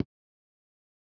pause-hover.wav